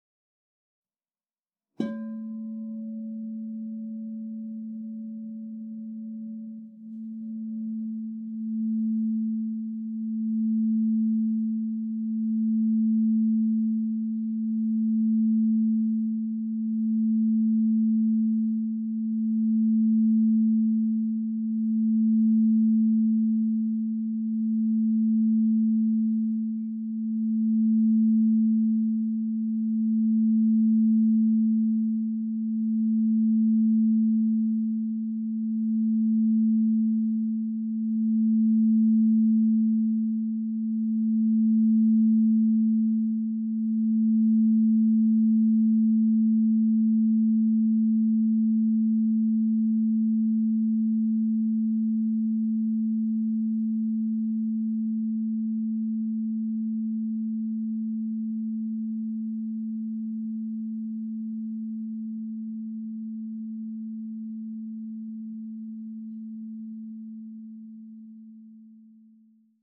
Meinl Sonic Energy 12" white-frosted Crystal Singing Bowl A3, 440 Hz, Brow Chakra (CSBM12A3)
The white-frosted Meinl Sonic Energy Crystal Singing Bowls made of high-purity quartz create a very pleasant aura with their sound and design.